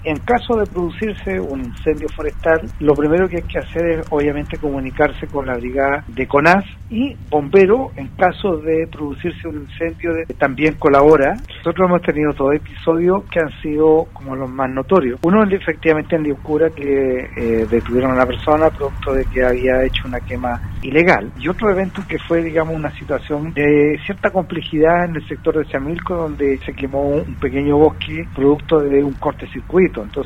En conversación con Radio Sago, los alcaldes de ambas comunas se refirieron a la situación de altas temperaturas pronosticado para estos días en la región y con ello la posible ocurrencia de incendios forestales.